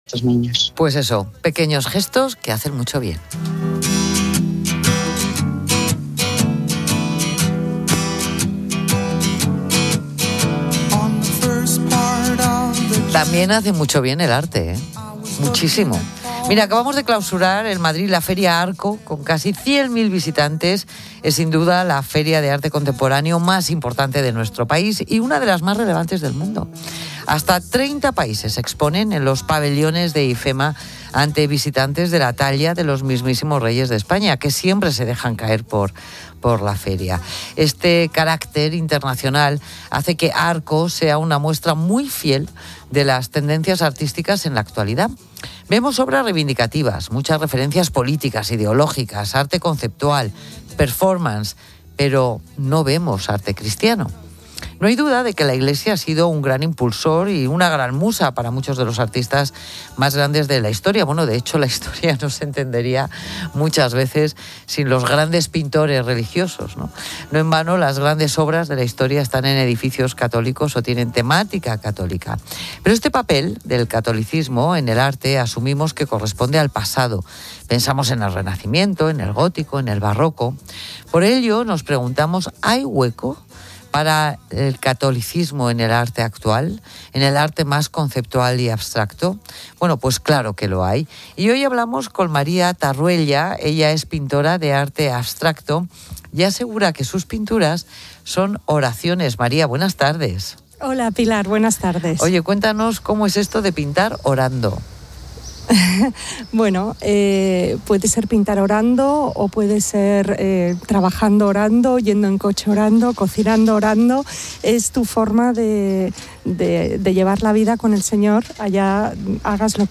Este fue el punto de partida en el programa 'Mediodía COPE', presentado por Pilar García de la Granja, para debatir sobre el espacio que ocupa el catolicismo en las corrientes artísticas actuales.